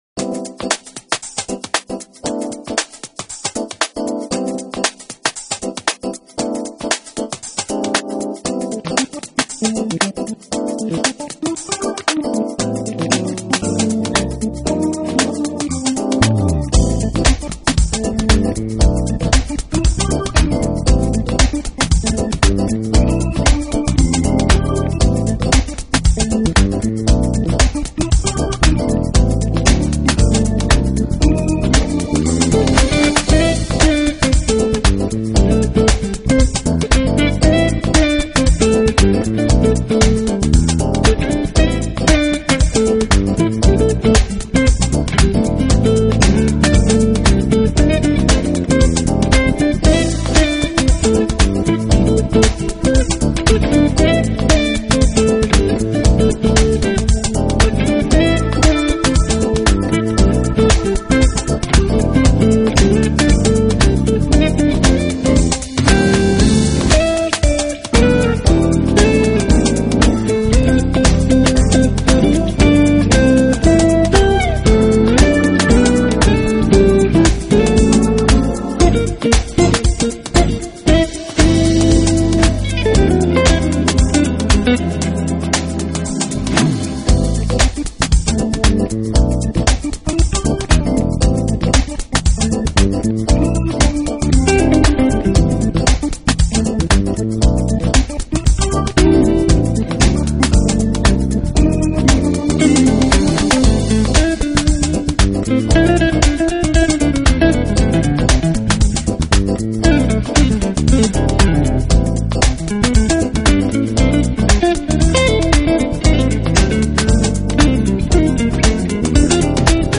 音乐类型：Jazz
keyboards,  guitar,  bass,  programming
tenor  saxophone,  flute
trumpet,  flugelhorn
piano,  keyboards,  programming
drums